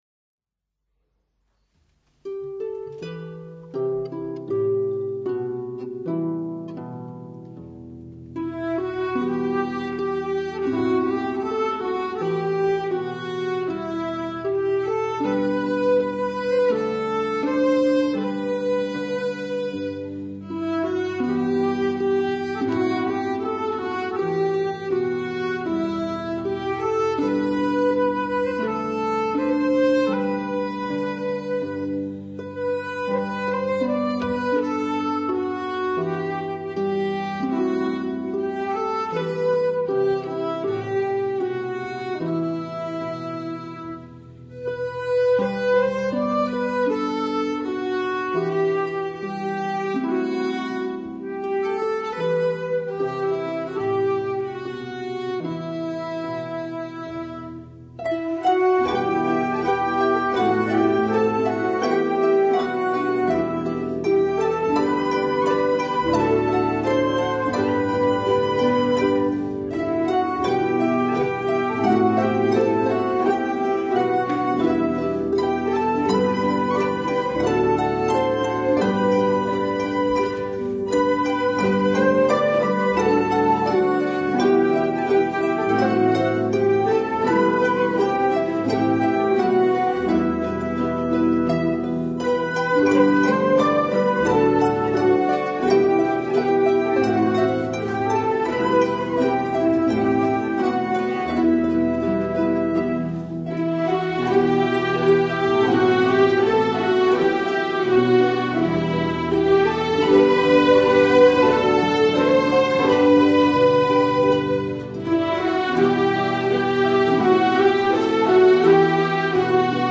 folk orchestra
is a simple but deep and moving Welsh melody
played as a hornpipe (dotted) or as a reel.